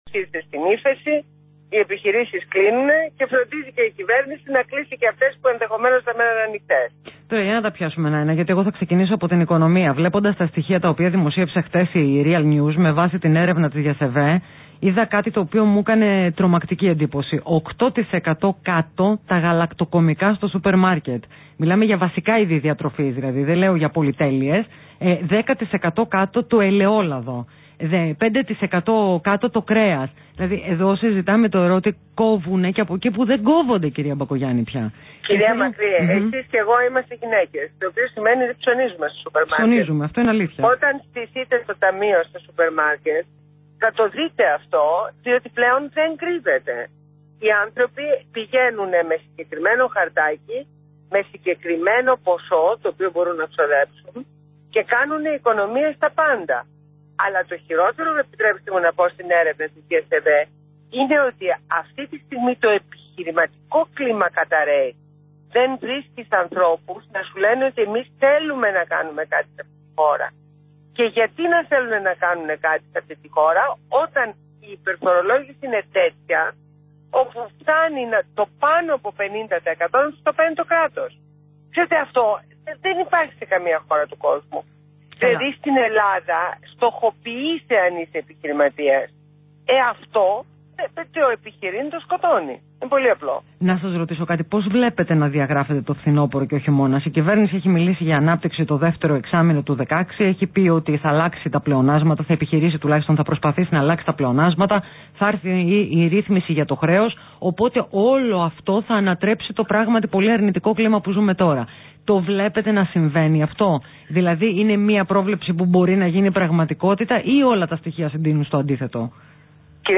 Συνέντευξη στο ραδιόφωνο του Real FM